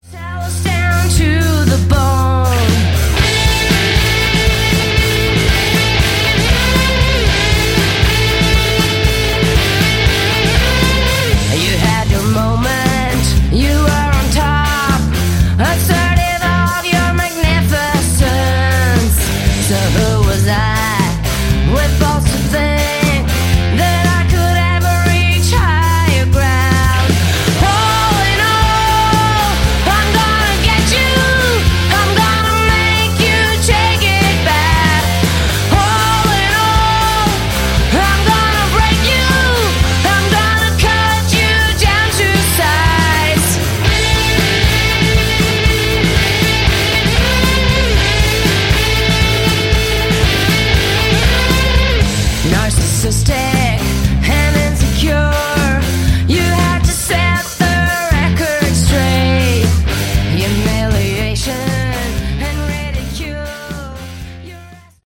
Category: Hard Rock
Good FF Modern Hard Rock!